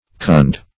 (k[u^]nd)